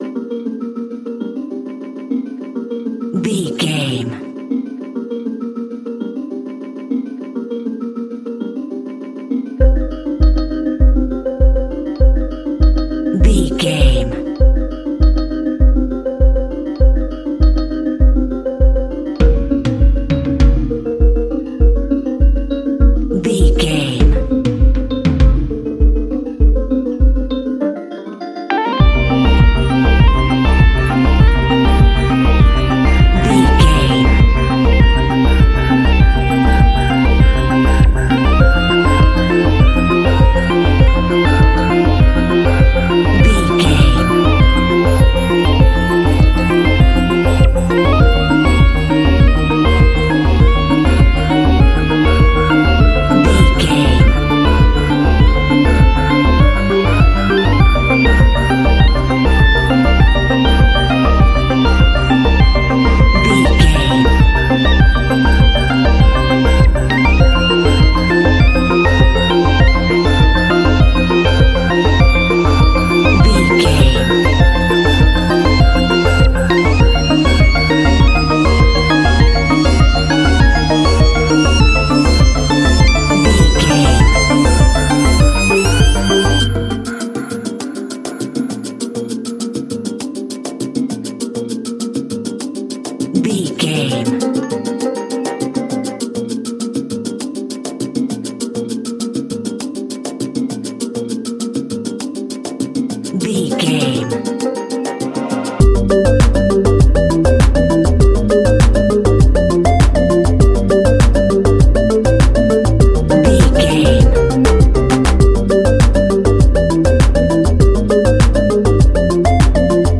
Dorian
Fast
industrial
meditative
mechanical
hypnotic
ethereal
dark
driving
groovy
tension
synthesiser
electric piano
percussion
drum machine